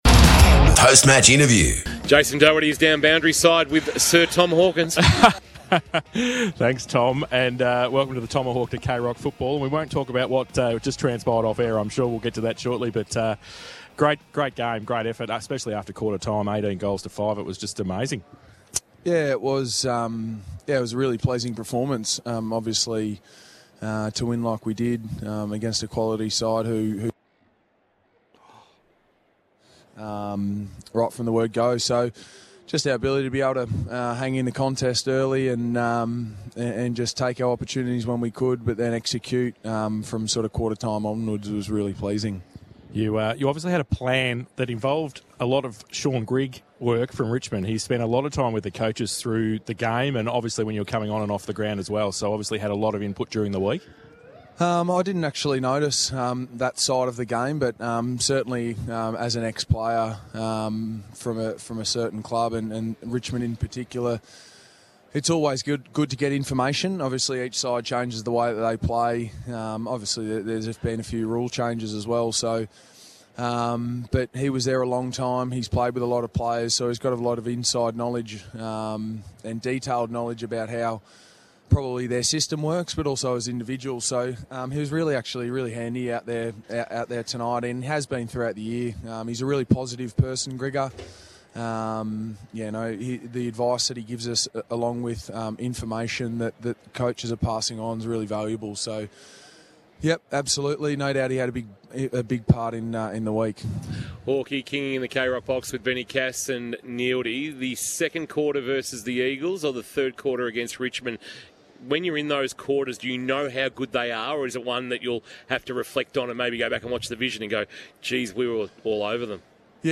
POST-MATCH: TOM HAWKINS - Geelong